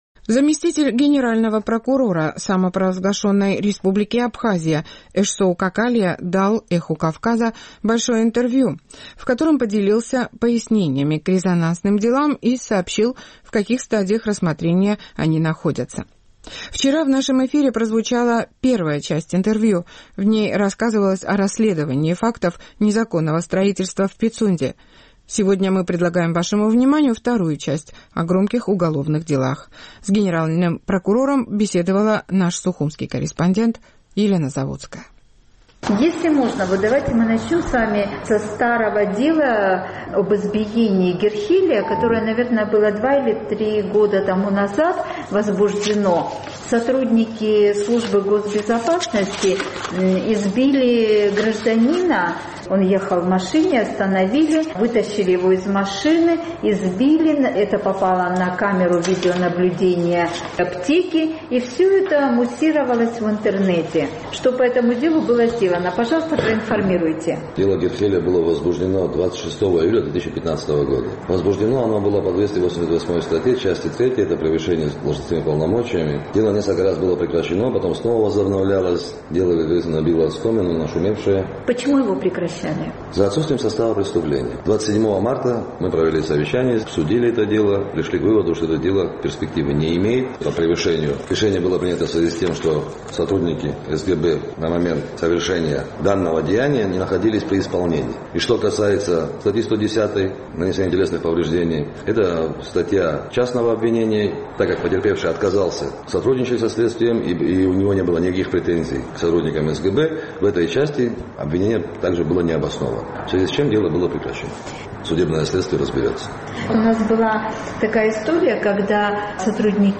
Заместитель генпрокурора Абхазии Эшсоу Какалия дал «Эху Кавказа» большое интервью, в котором дал пояснения к резонансным уголовным делам и сообщил, на какой стадии рассмотрения они находятся.